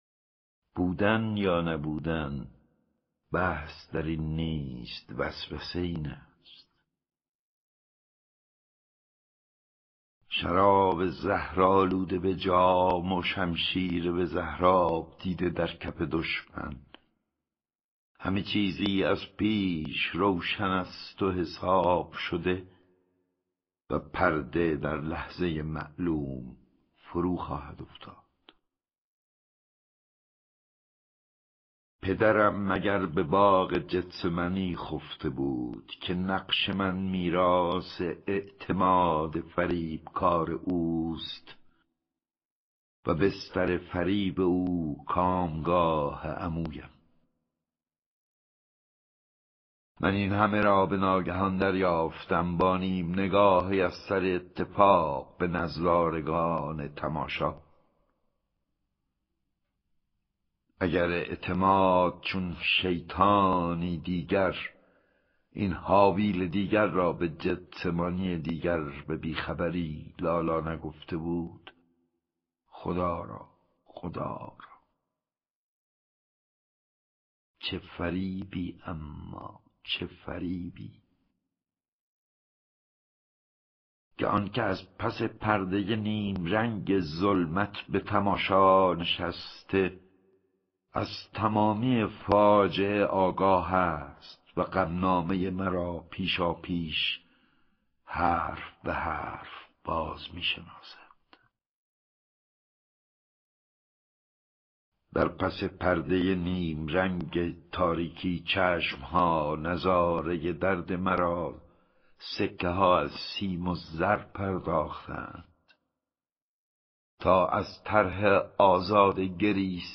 دانلود دکلمه هملت از احمد شاملو
گوینده :   [احمد شاملو]